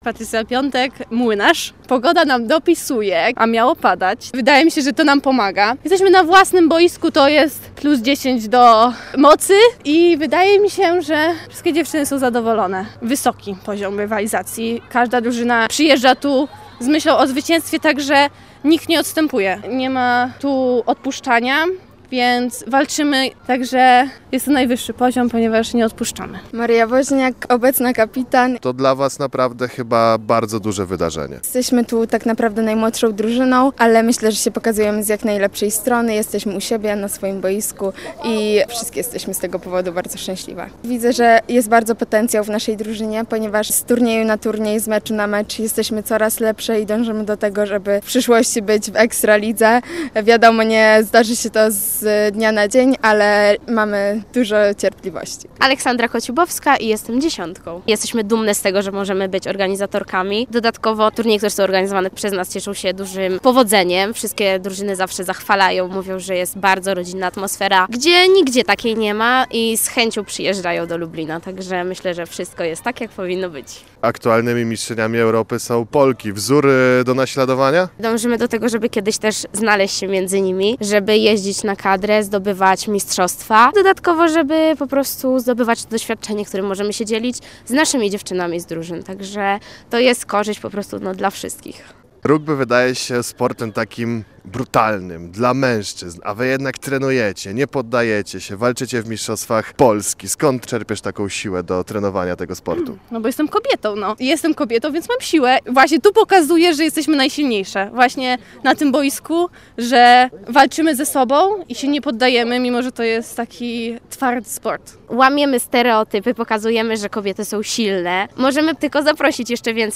Z rugbistkami Amazonek Budowlani Lublin rozmawiał